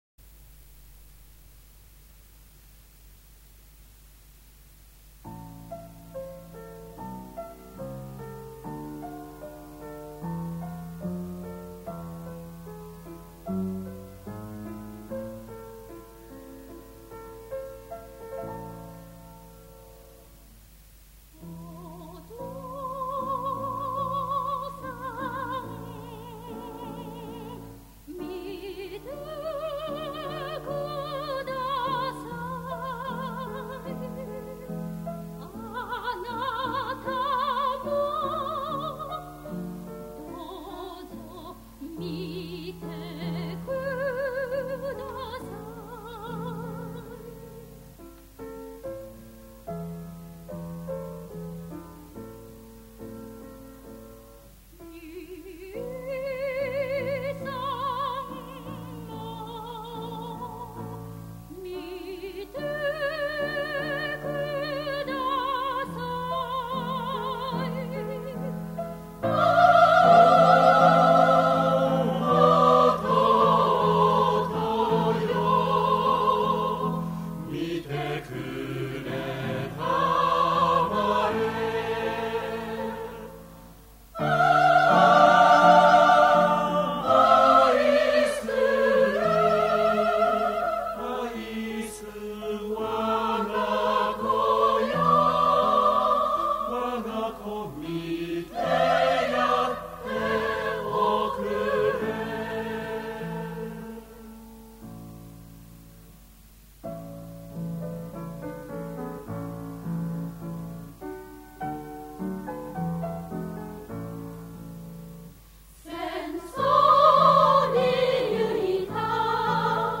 343 『きこしめし給え』 ―英霊に感謝し、靖国神社に奉納した私たちの涙の合唱をお聴きください。